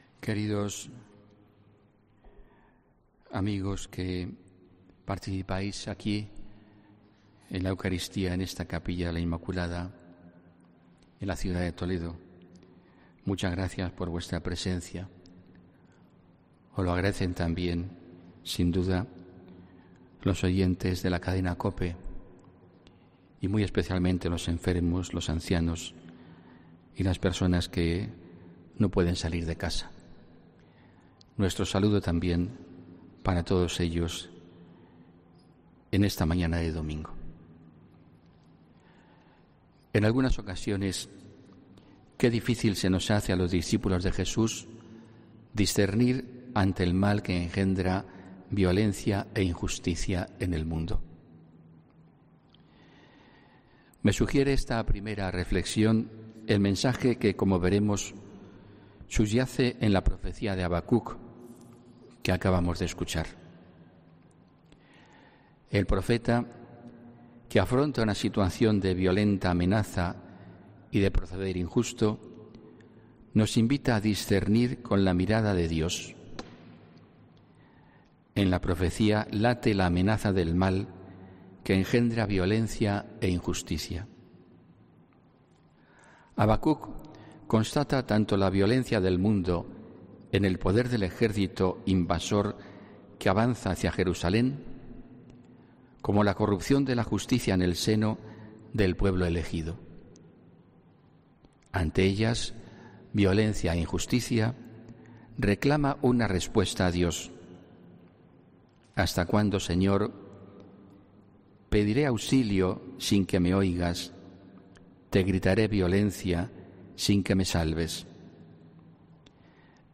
HOMILÍA 6 OCTUBRE 2019